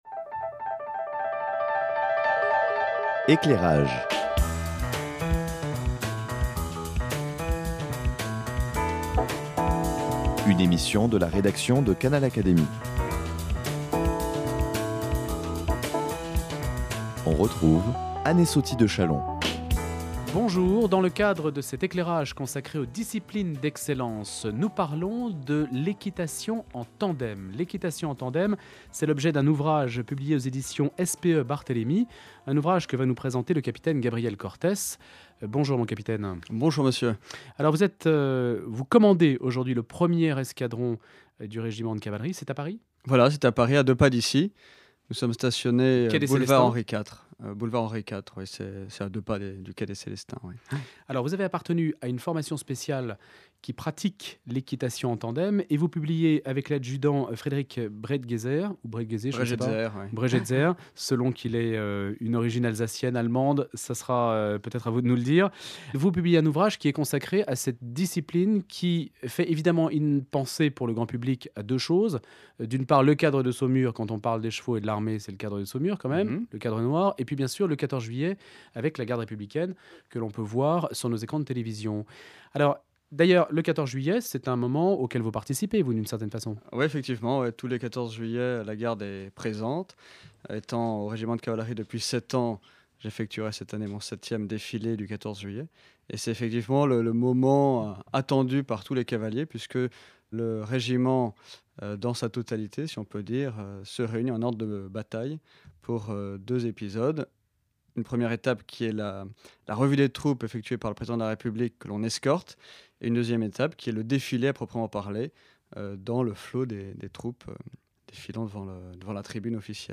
Dans cet entretien